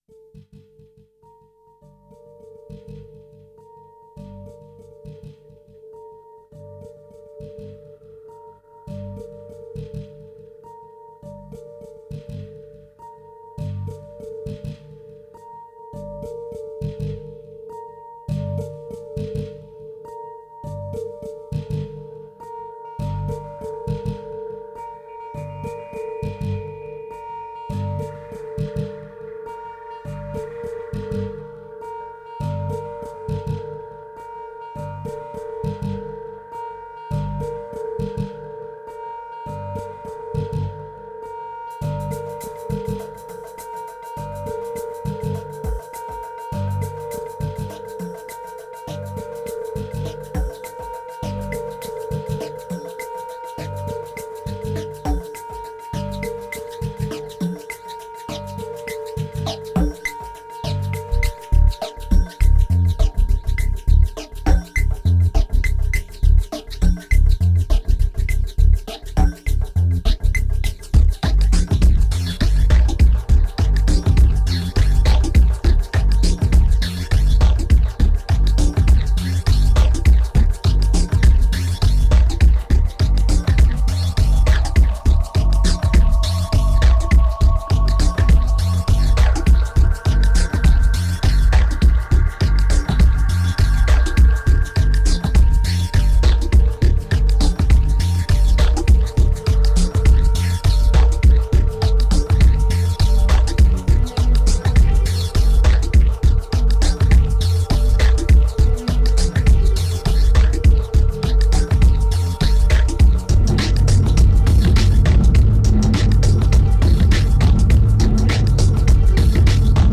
1831📈 - 0%🤔 - 102BPM🔊 - 2010-10-03📅 - -342🌟